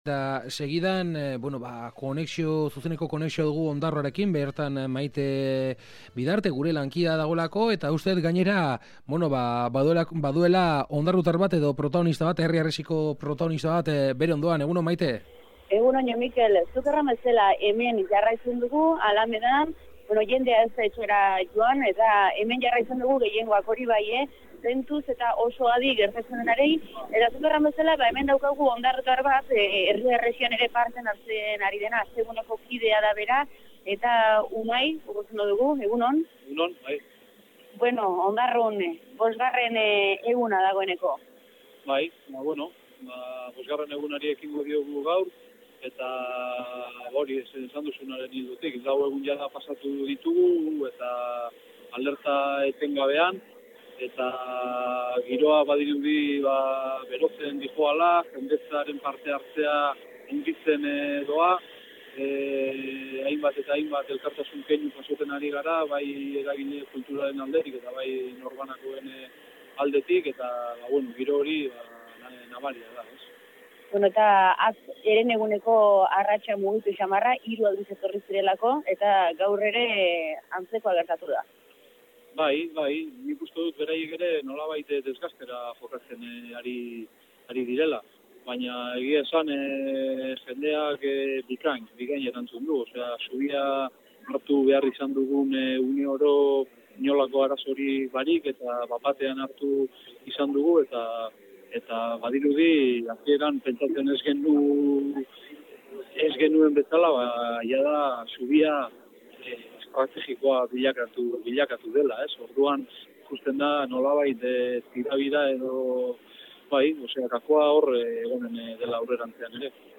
Aske Gunetik zuzenean eginiko konexioak, eguneko errapasoa!